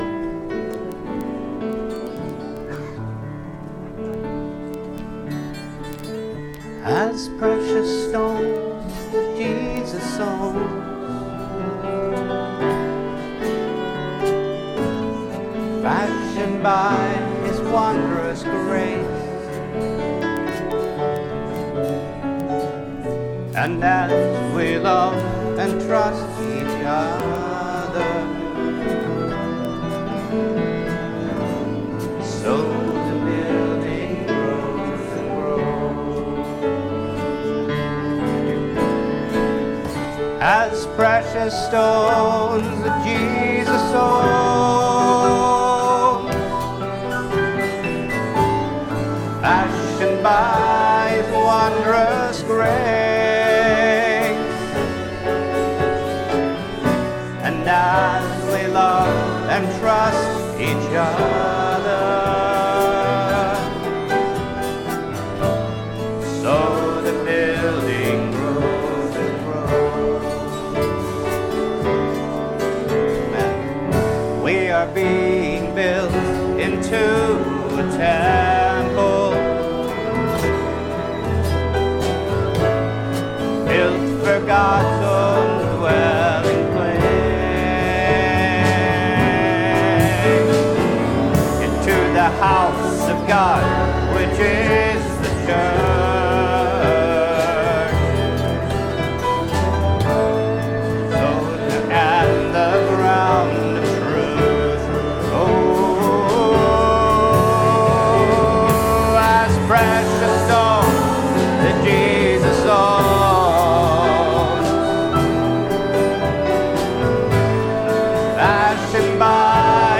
From Meetings: "Shepherds Inn Mar Conv 2026"